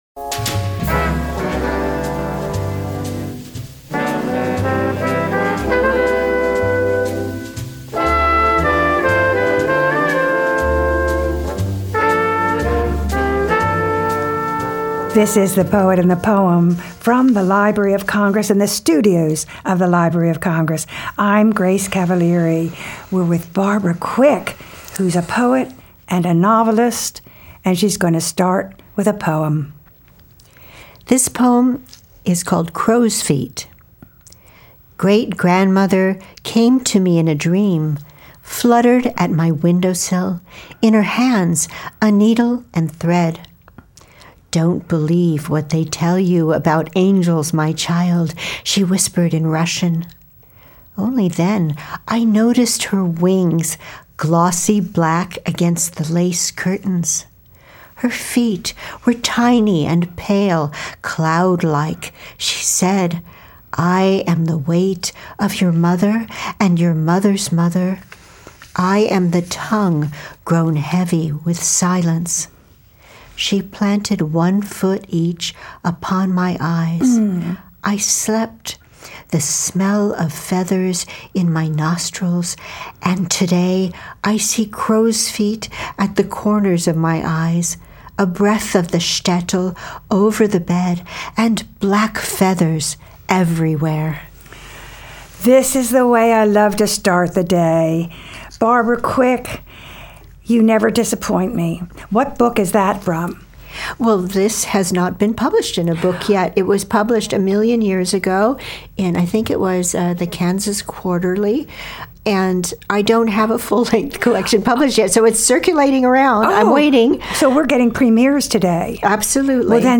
Interviews with U.S. Poets Laureate